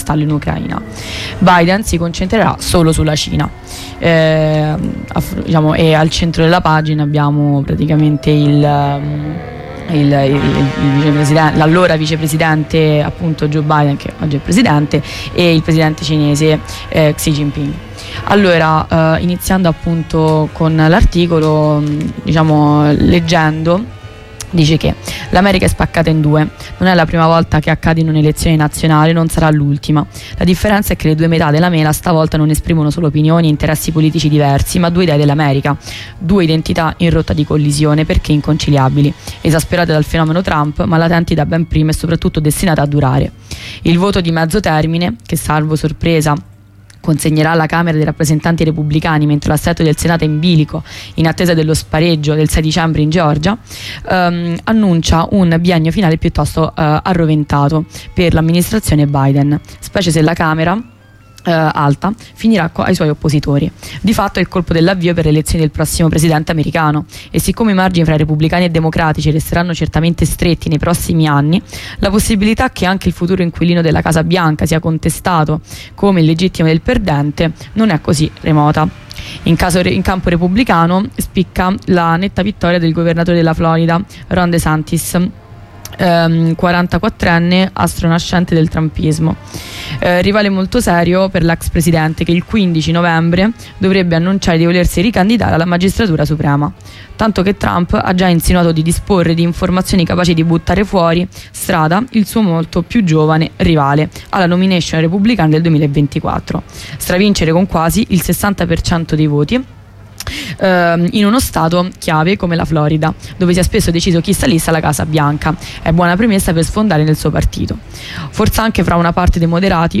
Rassegna stampa (10/11/2022)